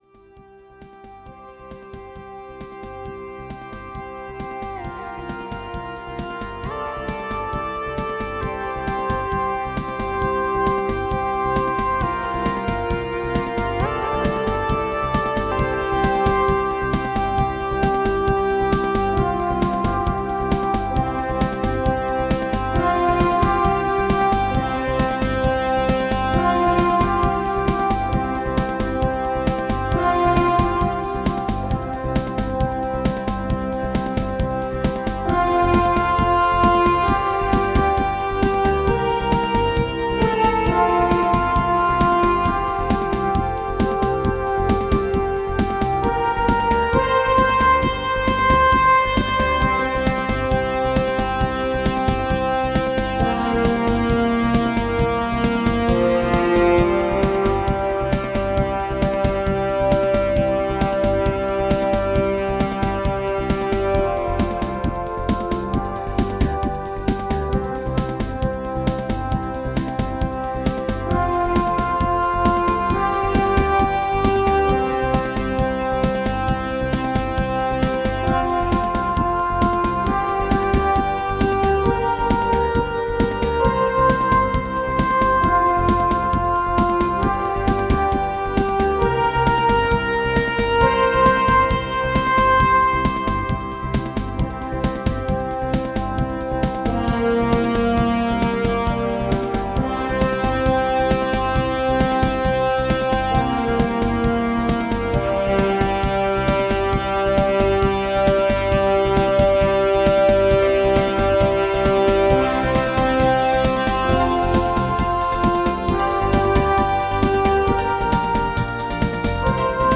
Tempo: 60 bpm / Datum: 28.01.2017